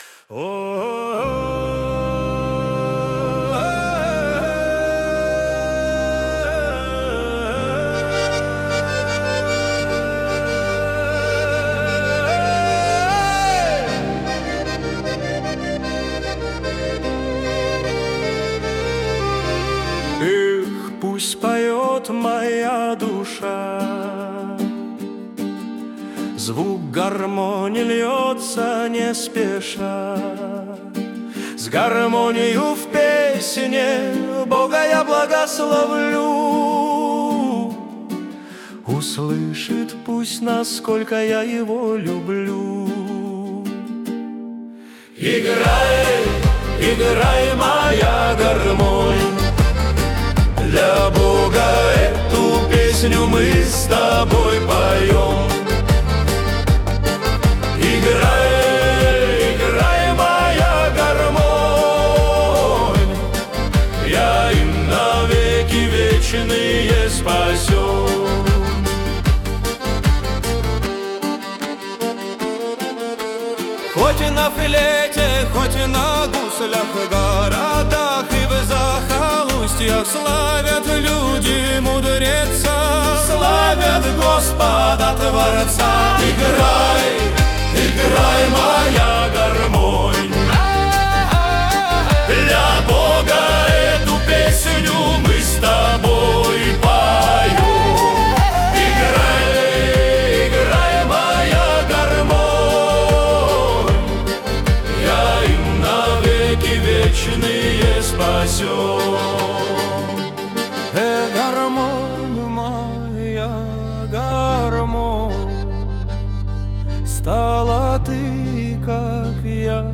песня ai
343 просмотра 998 прослушиваний 81 скачиваний BPM: 77